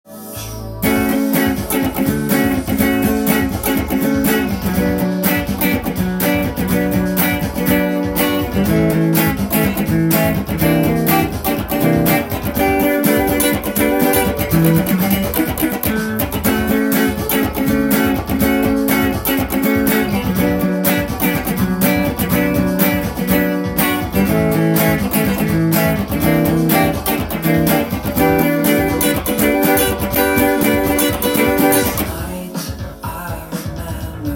音源にあわせて譜面通り弾いてみました
チューニング時の周波数は４３６になります。
この曲はバスドラが非常に前に出てくる16ビートの曲です。